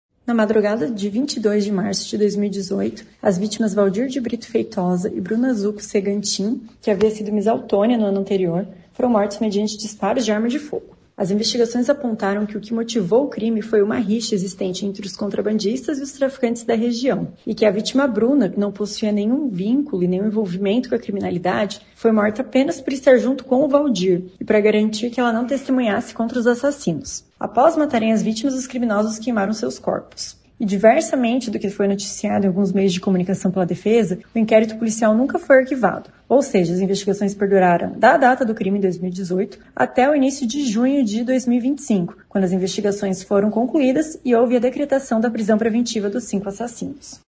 Ouça o que diz a promotora de Justiça Ana Carolina Lacerda Schneider: